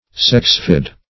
Search Result for " sexfid" : The Collaborative International Dictionary of English v.0.48: Sexfid \Sex"fid\, Sexifid \Sex"i*fid\, a. [Sex- + root of L. findere to split: cf. F. sexfide.]